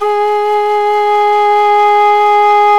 SULING VIB01.wav